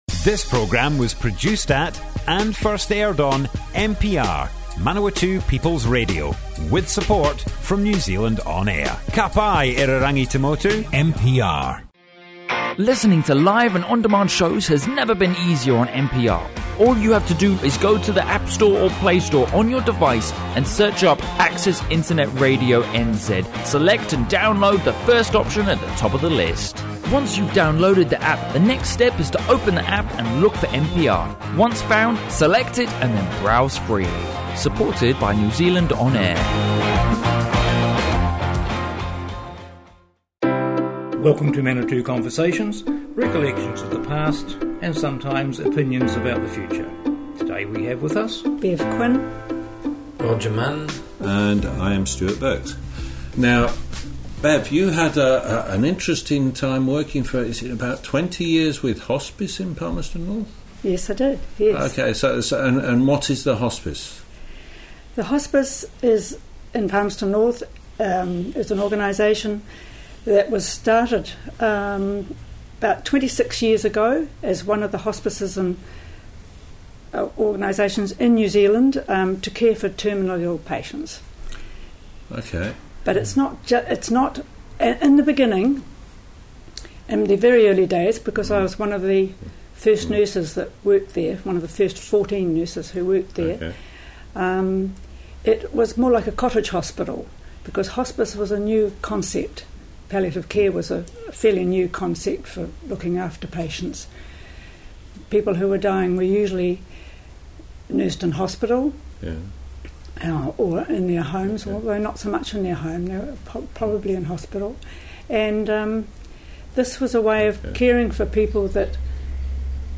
Manawatu Conversations More Info → Description Broadcast on Manawatu People's Radio 23 October 2018.
oral history